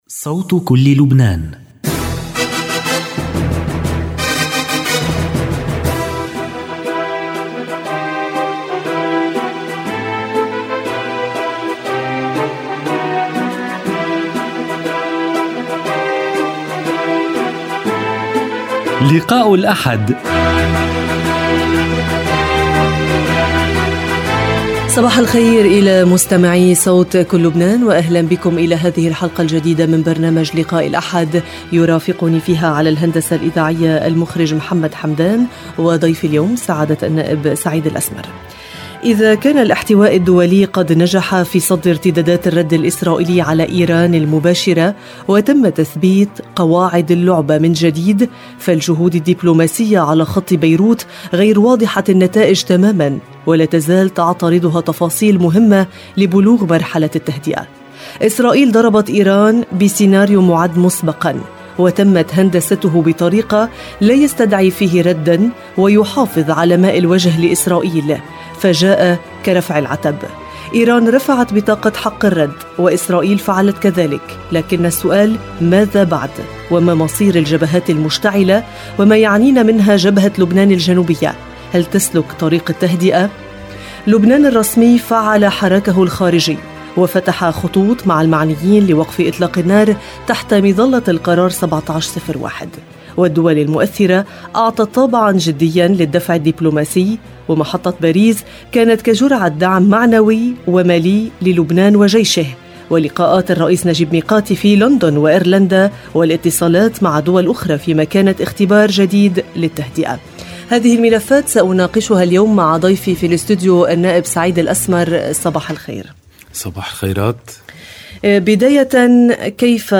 لقاء الأحد النائب سعيد الأسمر ورئيس لجنة الطوارئ الحكومية الوزير ناصر ياسين تحاورهما